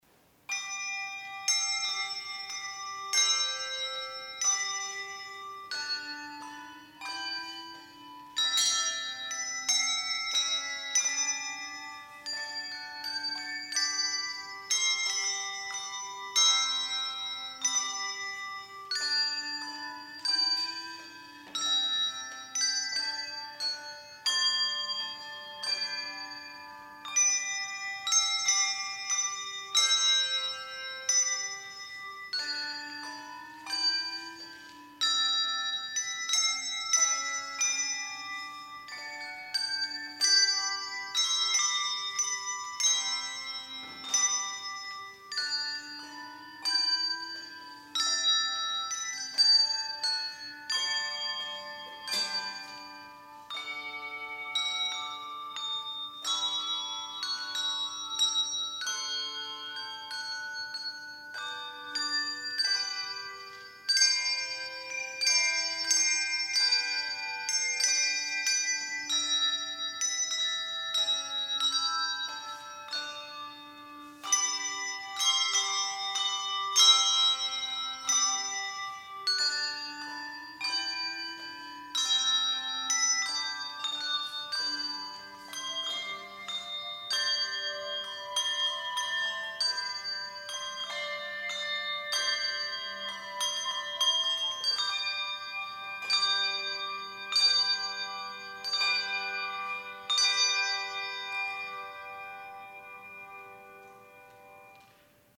VOLUNTARY St. Anthony Chorale (Franz Joseph Haydn, arr. Jane Patterson)
Performers:  Handbell Quartet